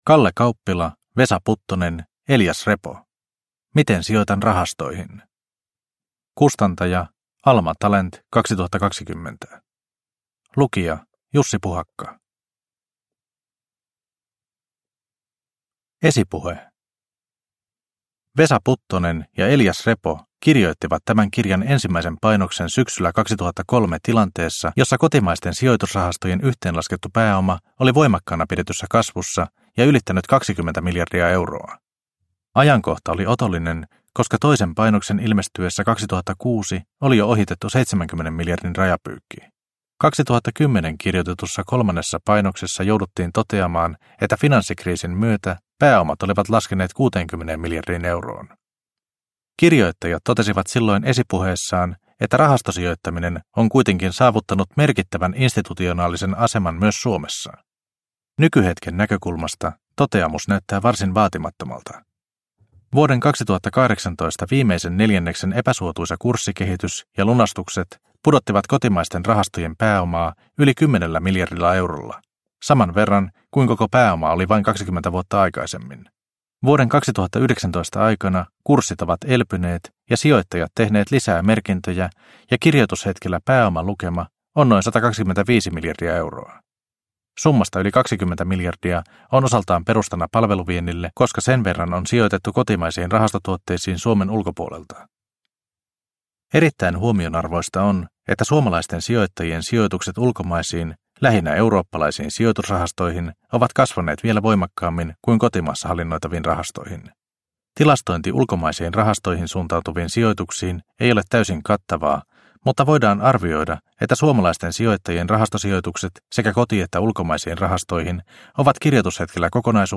Miten sijoitan rahastoihin – Ljudbok – Laddas ner